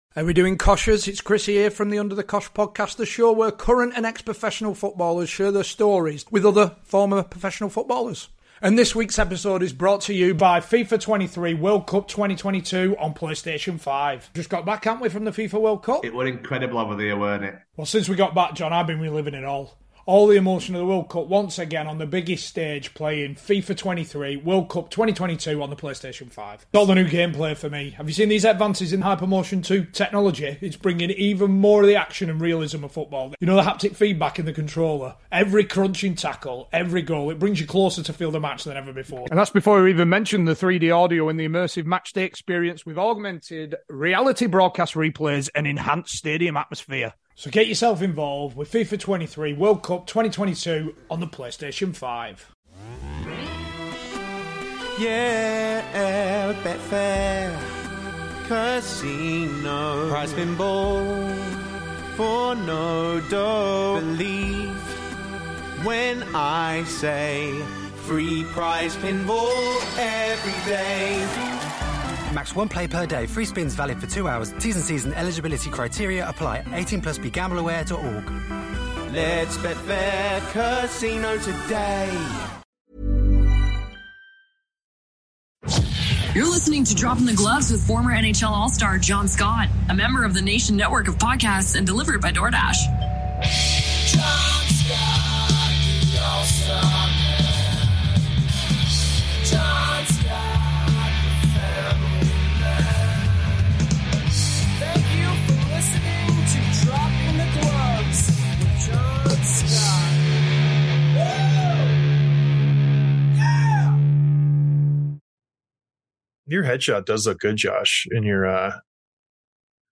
Interview with Josh Morrissey, Winnipeg Jets